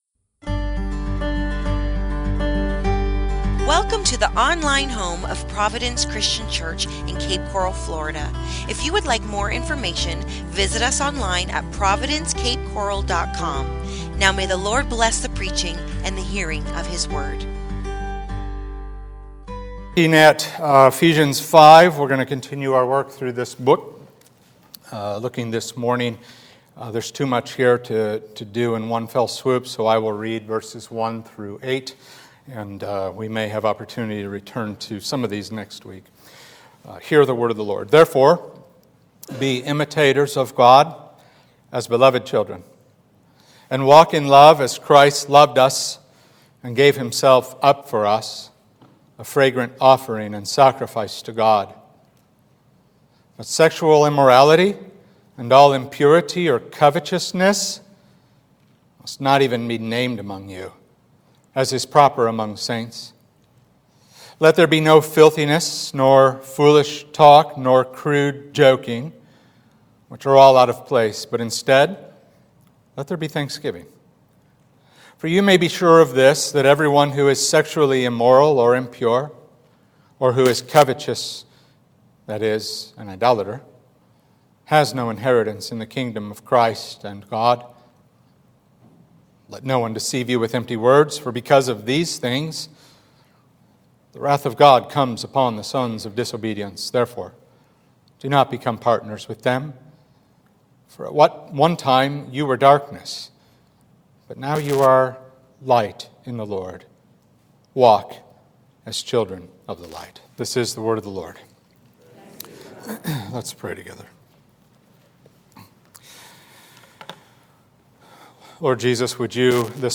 Walking on a Firm Foundation | SermonAudio Broadcaster is Live View the Live Stream Share this sermon Disabled by adblocker Copy URL Copied!